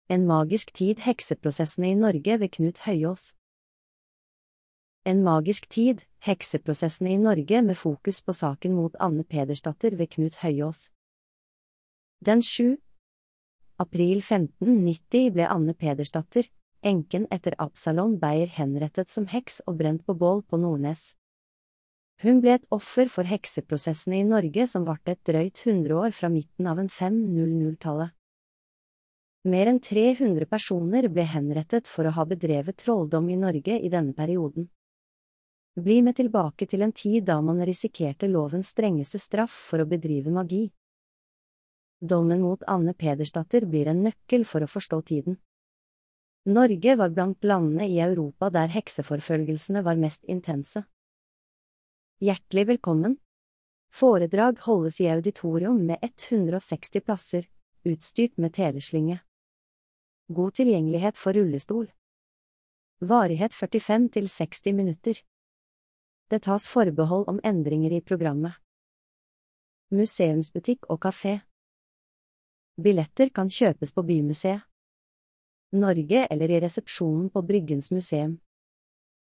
Populærvitenskapelige foredrag. Bryggens Museum.